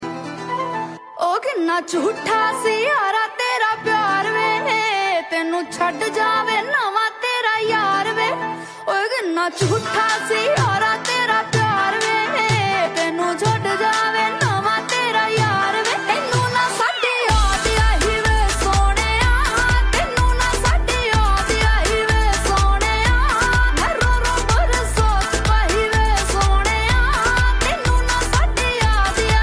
Soulful Melodies